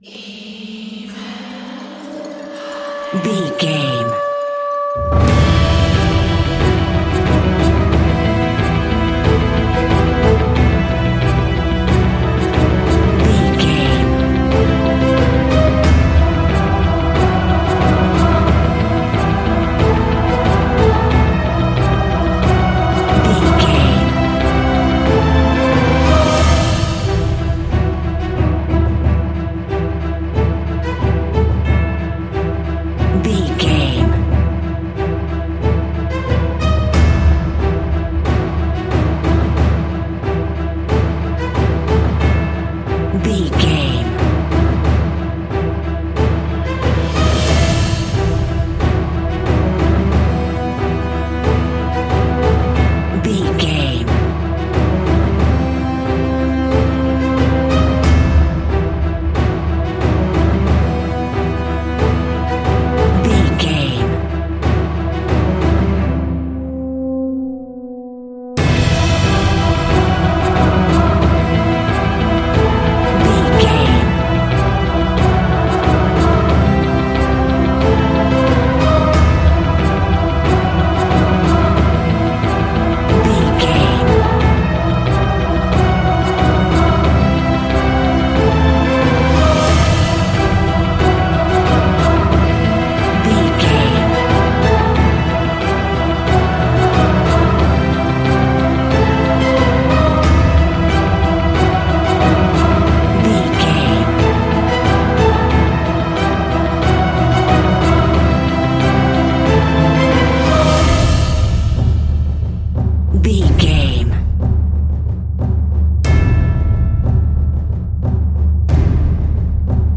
In-crescendo
Thriller
Aeolian/Minor
scary
ominous
dark
haunting
eerie
driving
intense
powerful
strings
brass
percussion
violin
cello
double bass
cinematic
orchestral
cymbals
gongs
viola
french horn trumpet
taiko drums
timpani